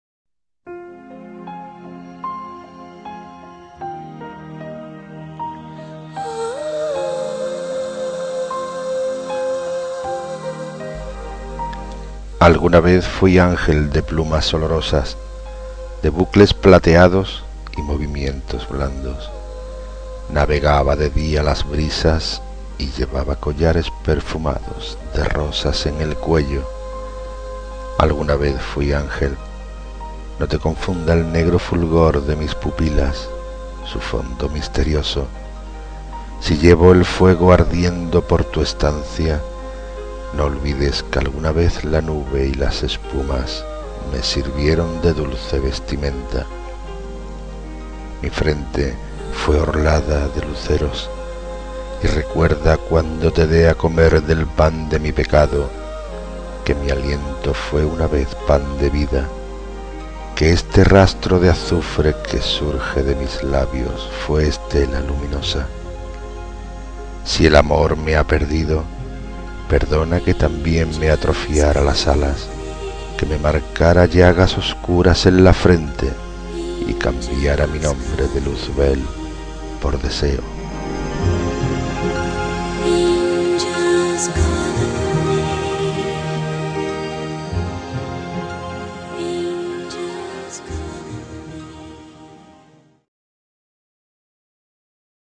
Inicio Multimedia Audiopoemas Metamorfosis.